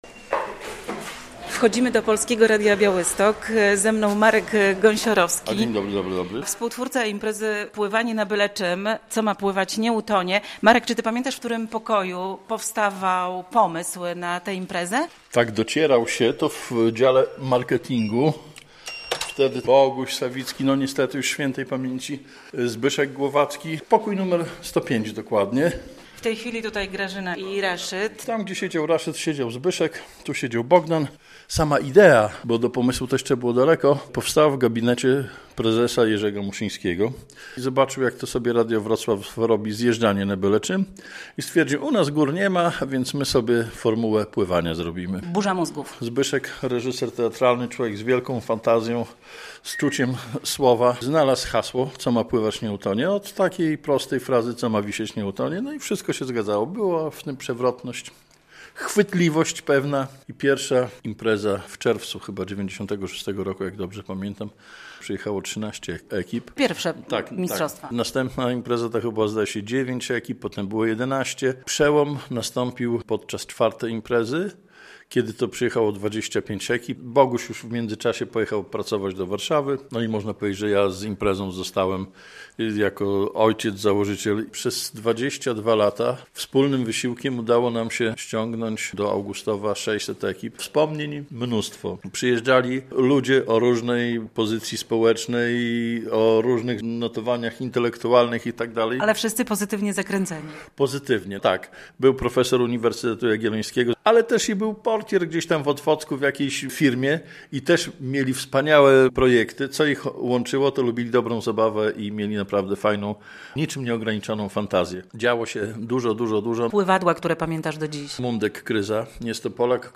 GOŚĆ RADIA BIAŁYSTOK